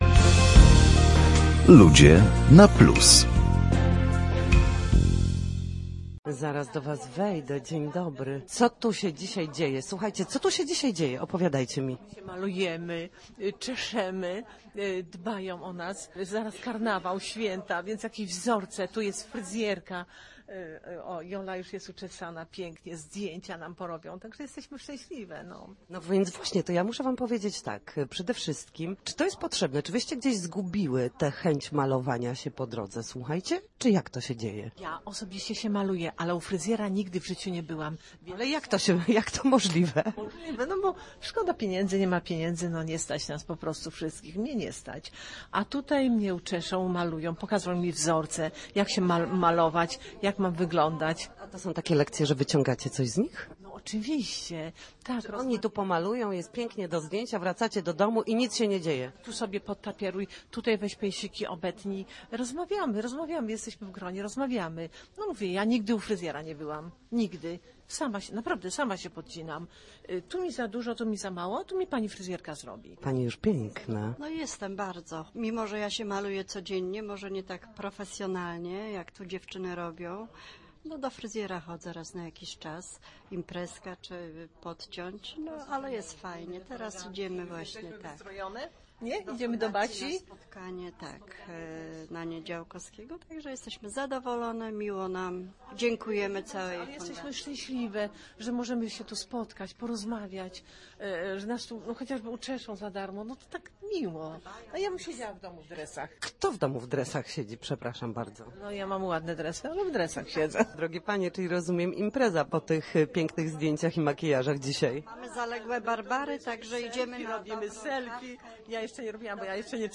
Metamorfoza w progresji. Zajrzeliśmy tam z mikrofonem